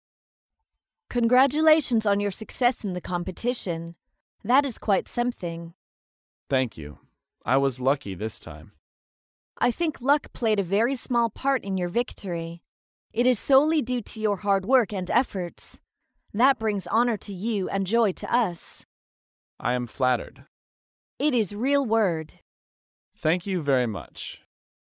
在线英语听力室高频英语口语对话 第128期:祝贺竞赛获胜的听力文件下载,《高频英语口语对话》栏目包含了日常生活中经常使用的英语情景对话，是学习英语口语，能够帮助英语爱好者在听英语对话的过程中，积累英语口语习语知识，提高英语听说水平，并通过栏目中的中英文字幕和音频MP3文件，提高英语语感。